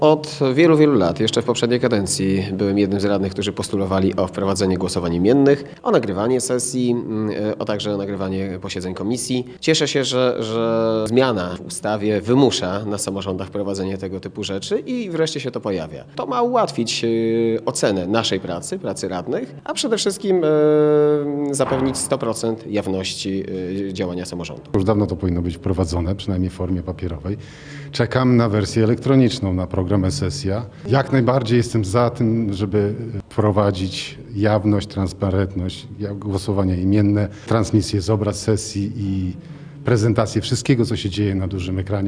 Pozytywnie o wprowadzeniu jawności głosowania wypowiadali się sami radni.
Ireneusz Dzienisiewicz i Robert Klimowicz.